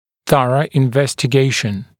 [‘θʌrə ɪnˌvestɪ’geɪʃn][‘сарэ инˌвэсти’гейшн]тщательное изучение